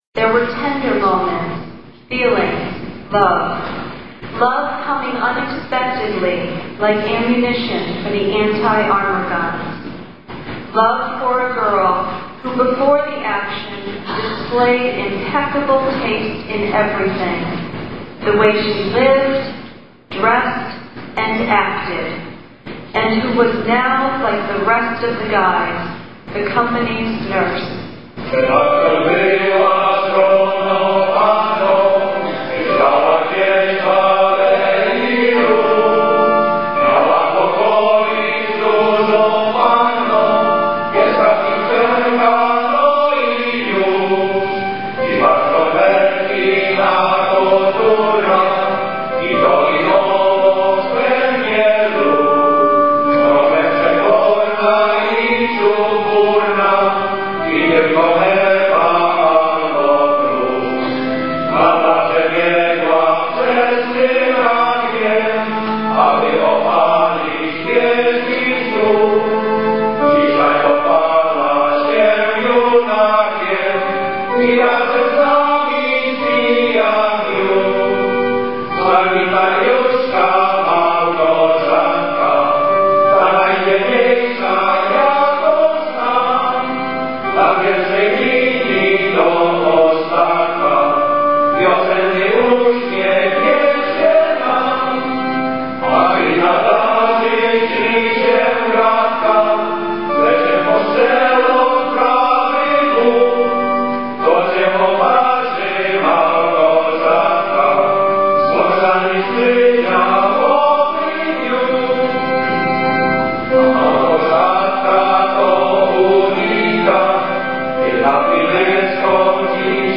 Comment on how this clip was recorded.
Presented on August 14th, 1994, at the St. Stanislaw's School in Chicopee, MA.